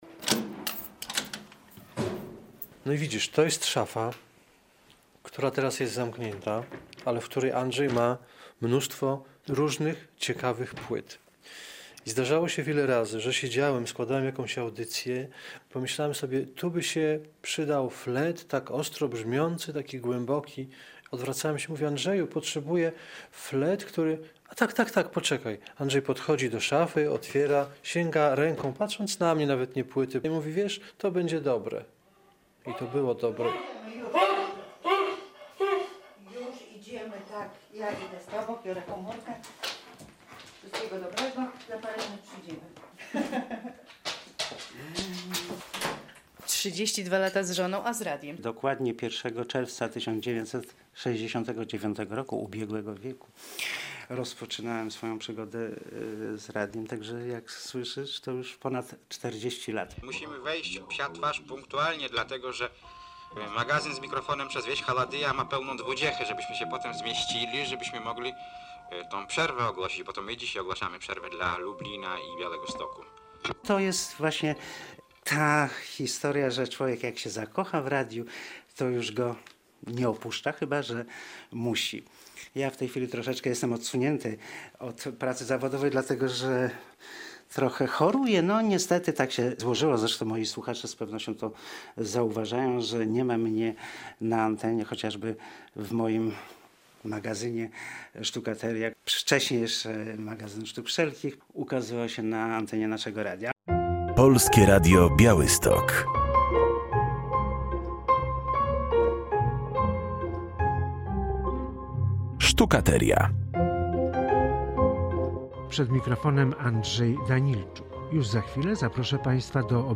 Radio Białystok | Reportaż | "Perfekcjonista.